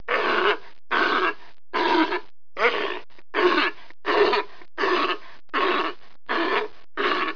دانلود آهنگ حیوانات جنگلی 85 از افکت صوتی انسان و موجودات زنده
جلوه های صوتی
دانلود صدای حیوانات جنگلی 85 از ساعد نیوز با لینک مستقیم و کیفیت بالا